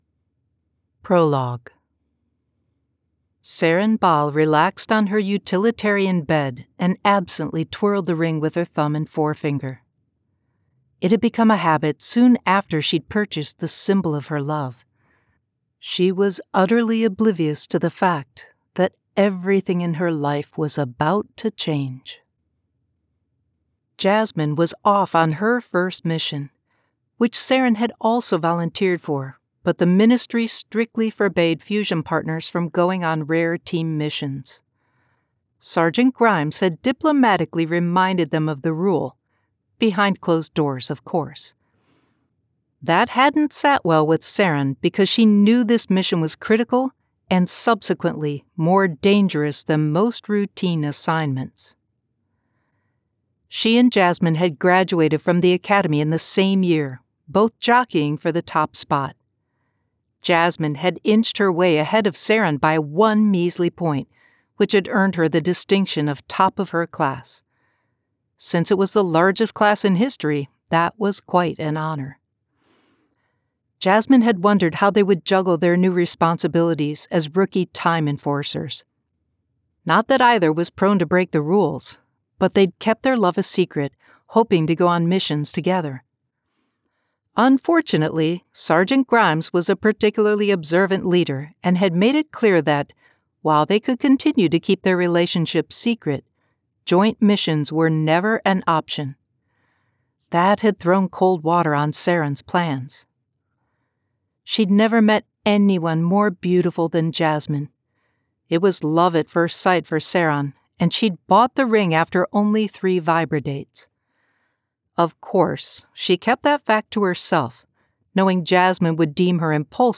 A Moment in Time By Annette Mori [Audiobook]